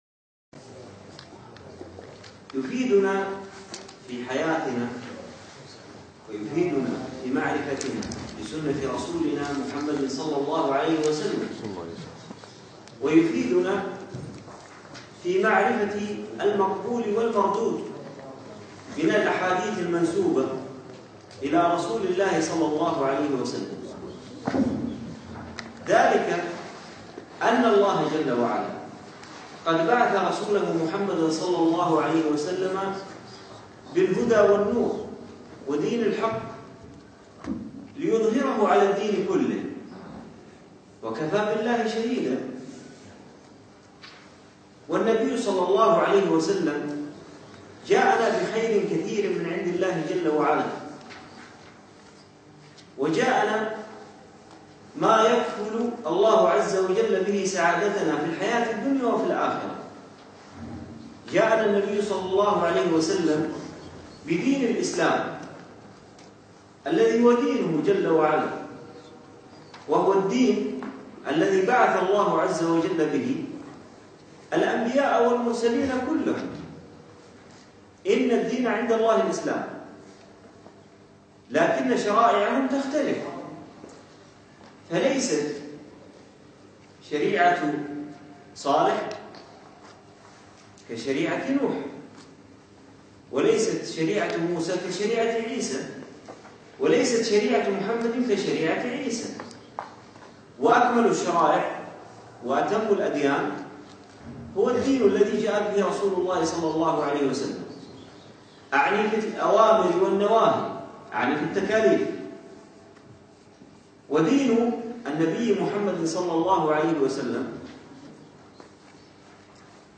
يوم الخميس 5 جمادى أول 1438 الموافق 2 2 2017 في مسجد زين العابدين سعد ال عبدالله